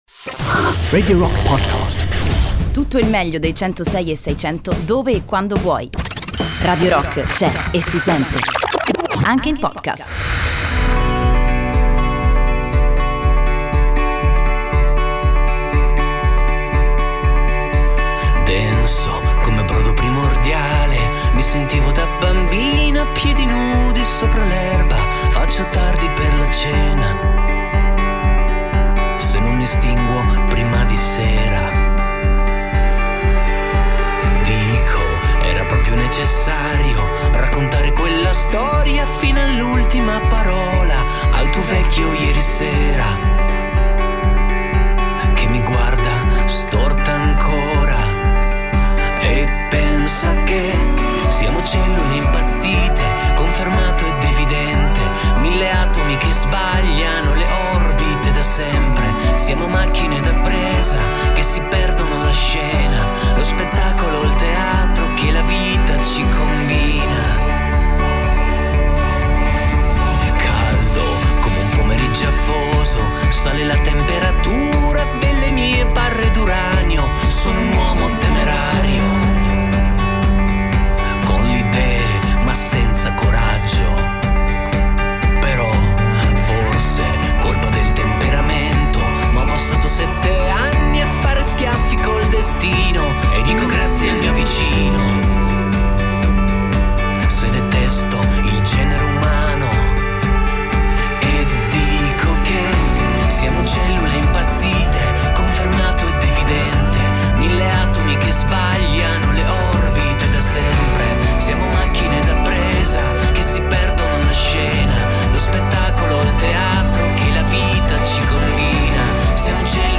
"Intervista": Il Nucleo (30-01-20)